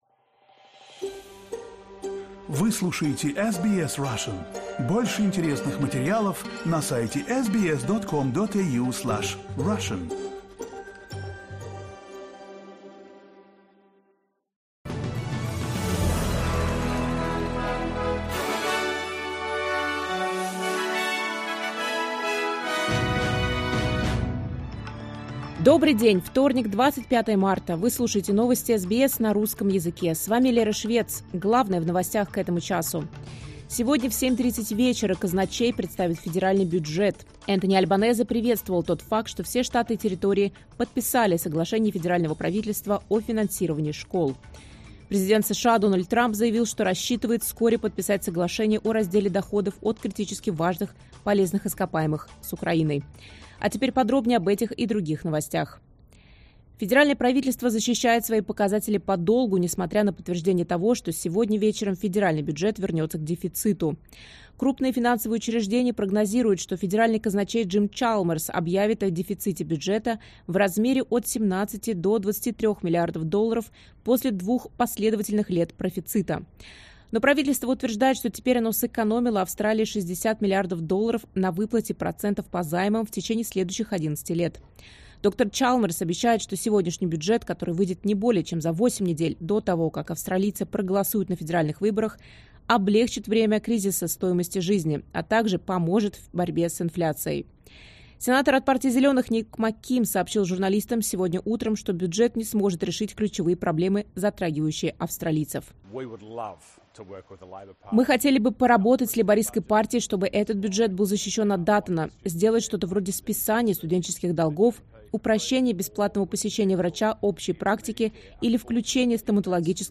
Новости SBS на русском языке — 25.03.2025